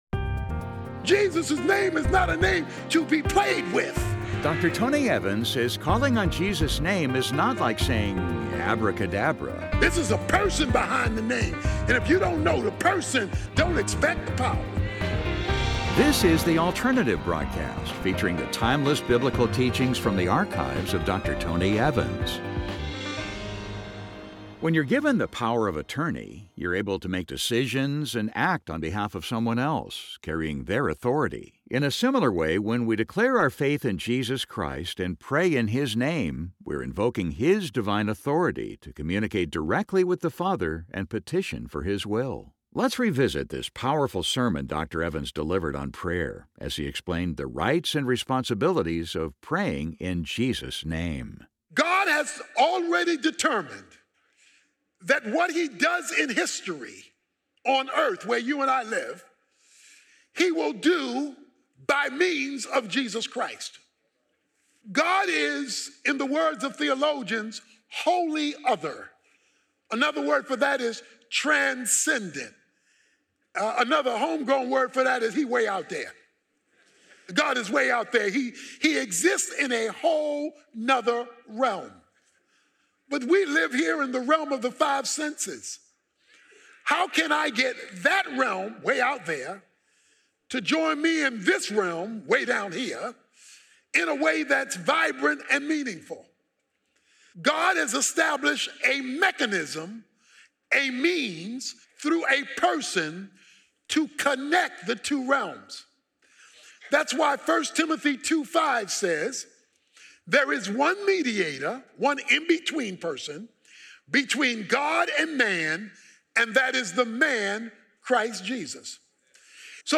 Praying in Jesus' Name, Part 2 Podcast with Tony Evans, PhD